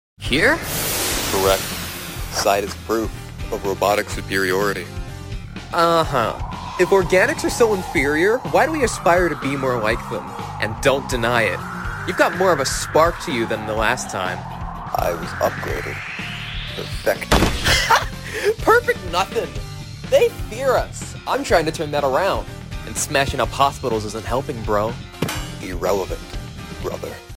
A Metal Sonic fandub that me and my friend did